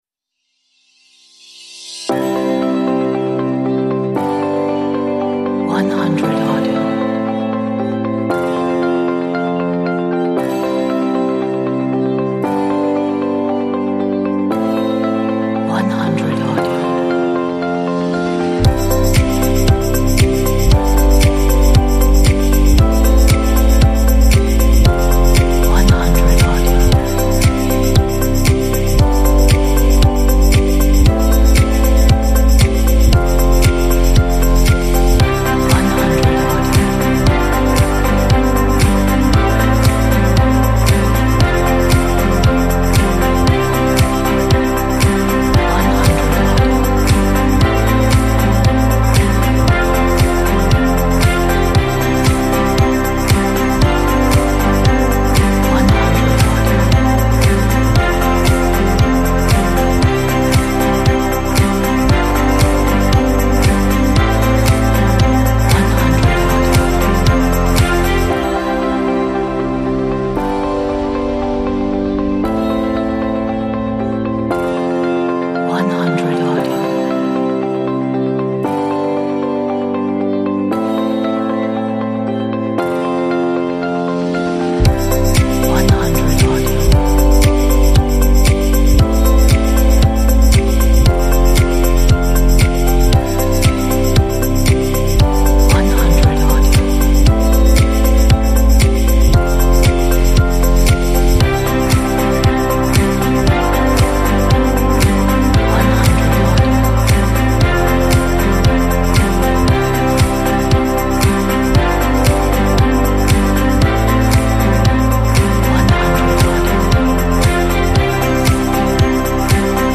a nice pop uplifting inspirational track
一首很棒的鼓舞人心的流行音乐